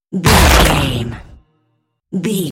Dramatic hit bloody
Sound Effects
Atonal
heavy
intense
dark
aggressive
hits